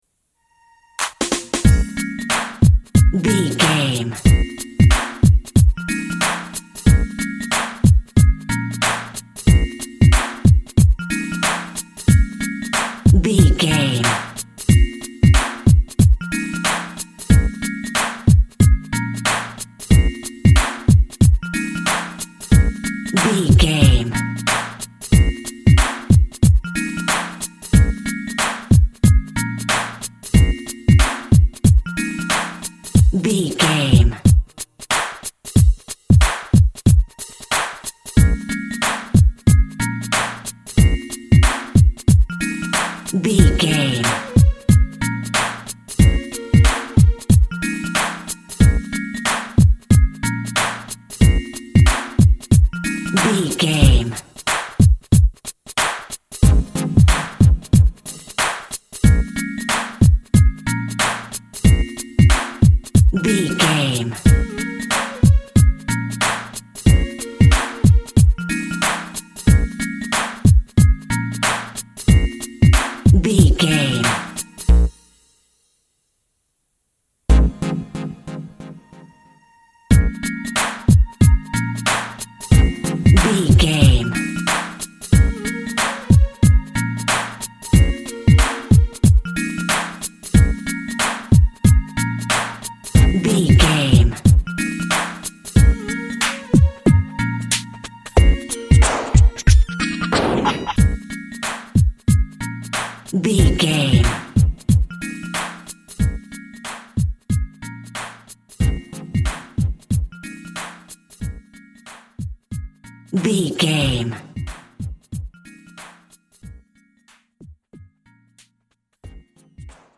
Deep Hip Hop Music.
Aeolian/Minor
B♭
synth lead
synth bass
hip hop synths